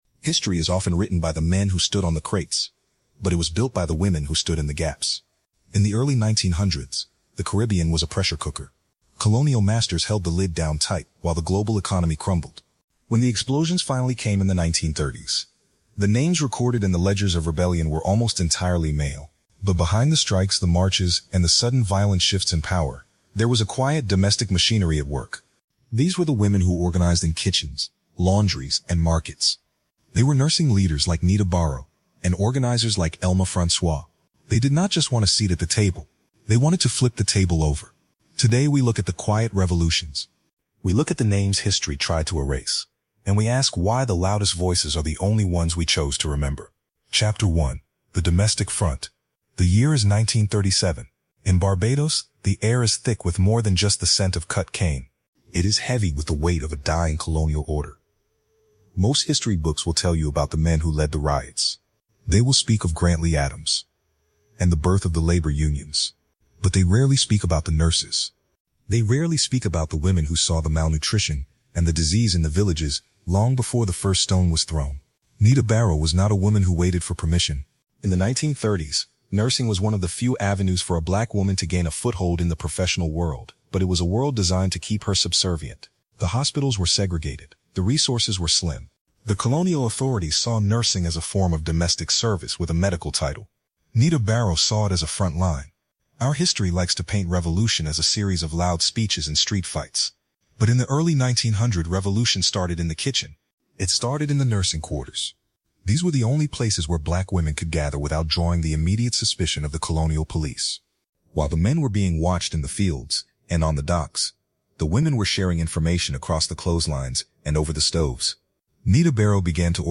We dive into the nineteen thirties labor rebellions, the role of market women in Trinidad, and the nursing reforms that changed Barbados. This is a gritty, documentary-style look at the erasure of female leadership in the Caribbean and why their stories are essential to understanding our history today.